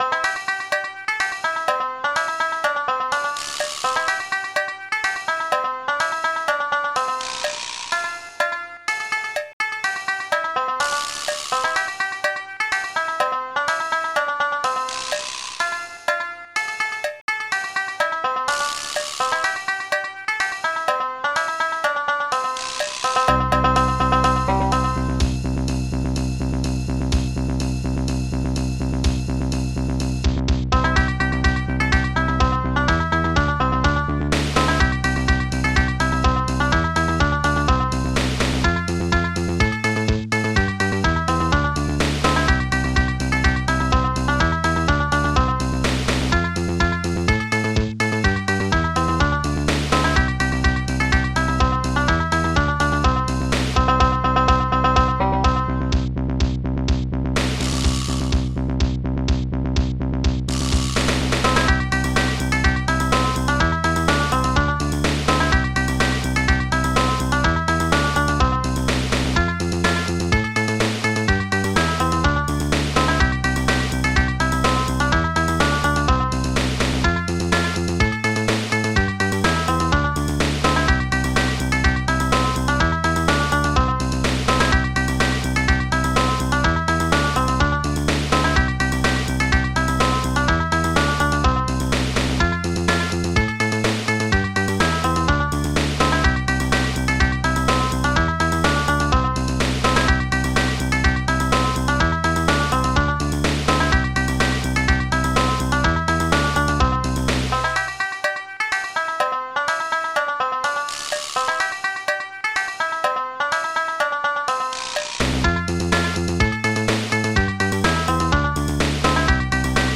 Protracker Module
st-02:banjo st-05:snake st-05:ridecymbal1 st-05:cowbell3 st-03:bassguitar9 st-05:bassdrum12 st-05:snare14